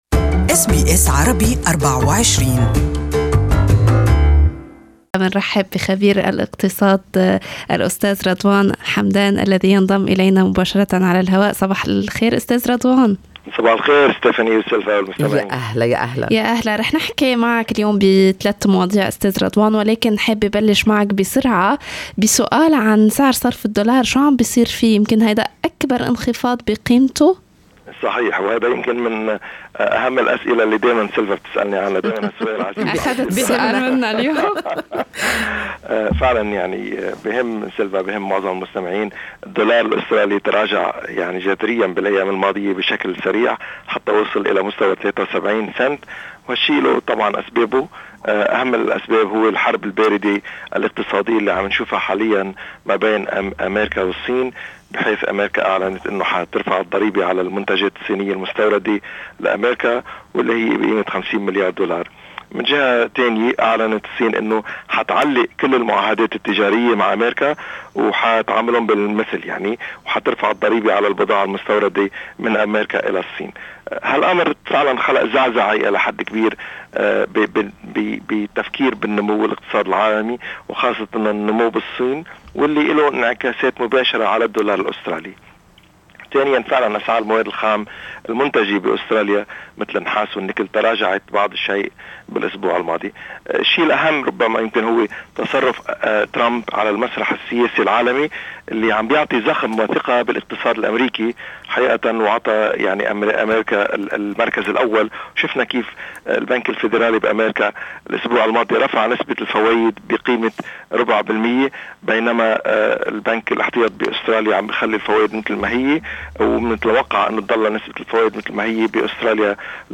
Economic segment with economist